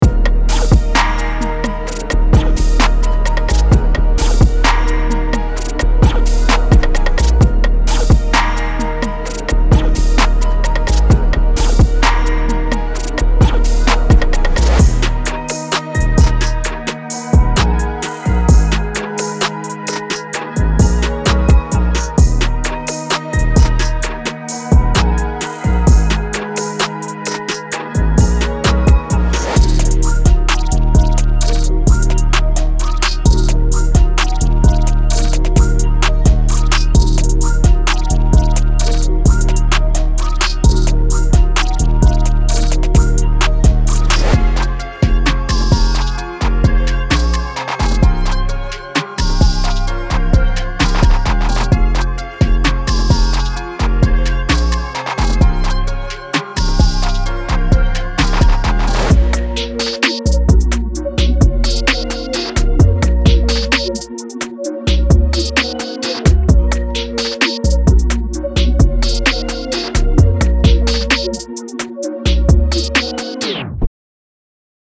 Hip-Hop / R&B Trap
• 15 Bass One Shots;
• 49 Synth Loops;